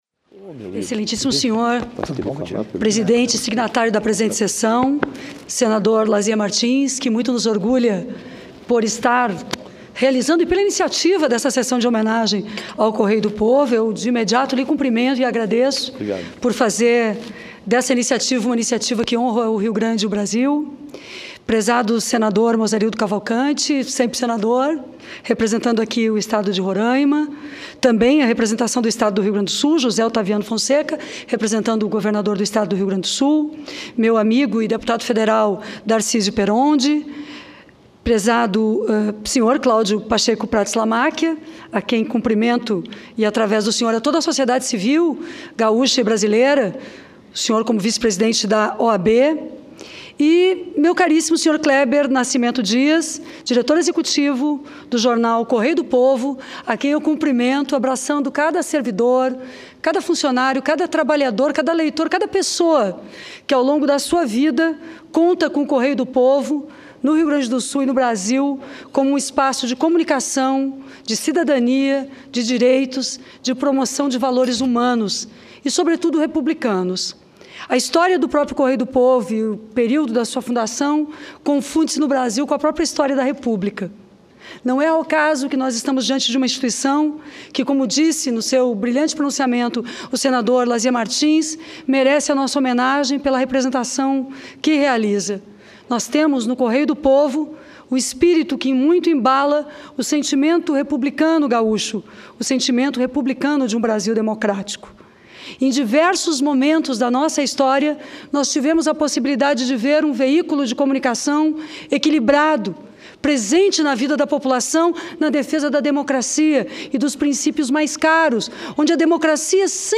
Sessão especial para comemorar os 120 anos do jornal "Correio do Povo"
Pronunciamento da deputada Maria do Rosário